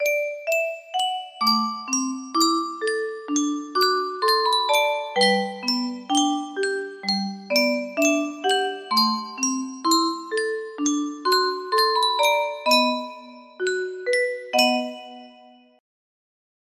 Clone of Yunsheng Custom Tune Music Box - For Me and My Gal music box melody